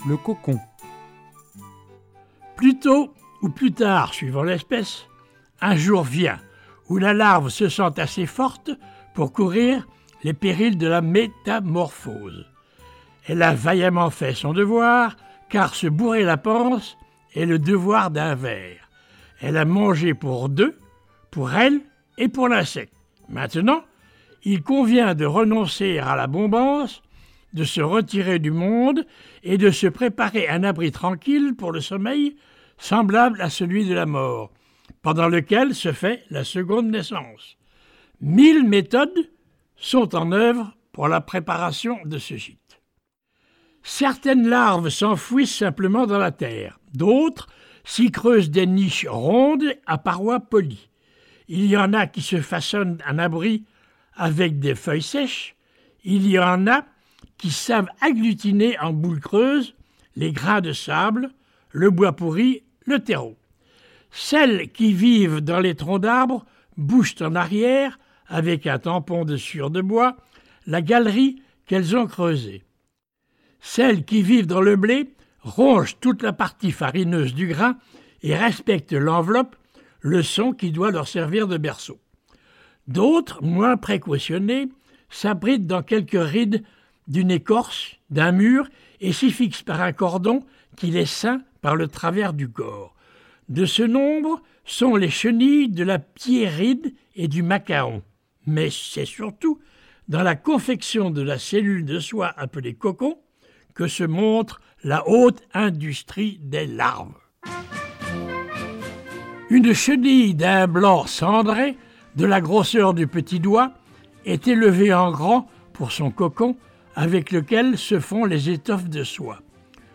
Le cocon, de Jean-Henri FABRE - Bibliothèque sonore
Récits de l'Oncle Paul, de Jean-Henri FABRE : Le cocon - Texte audio